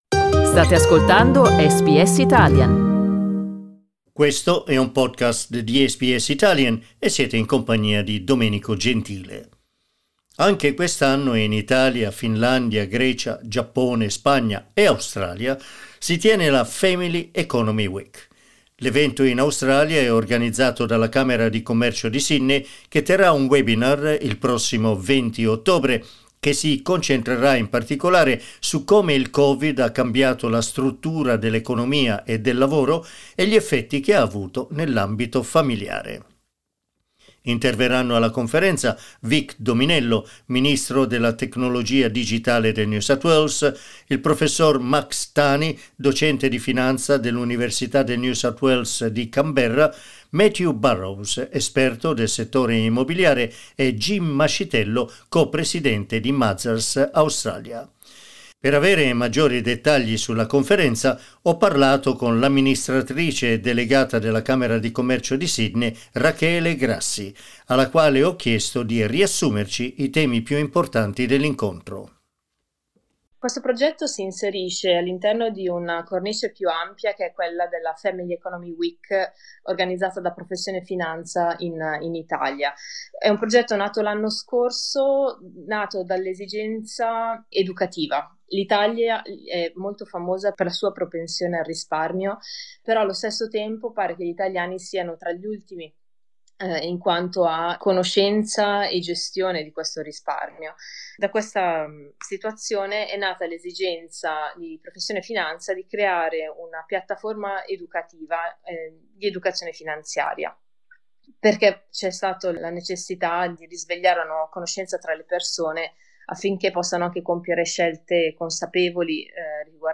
Ascolta l'intervista: LISTEN TO Family Economy Week 2021 si tiene anche in Australia SBS Italian 10:25 Italian Per maggiori informazioni sulla Family Economy Week, clicca qui.